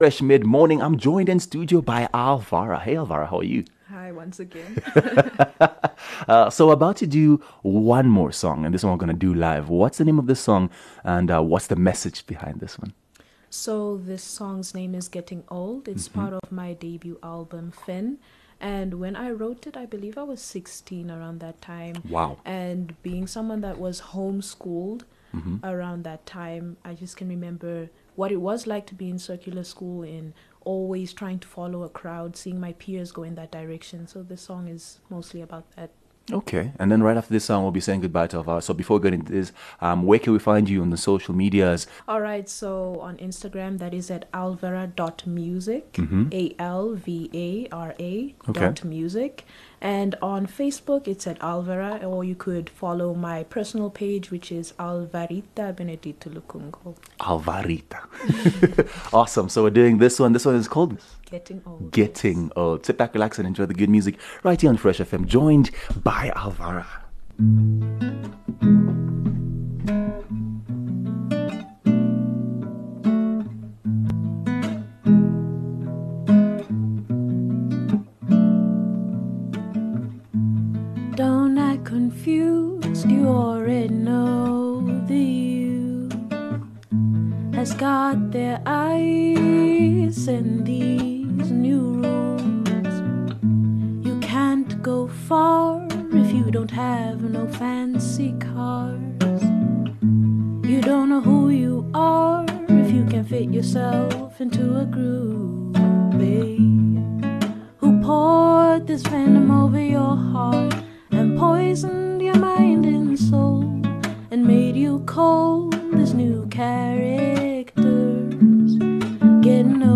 Live Music
a local musician and guitar player came into studio and blessed us with some of her original songs.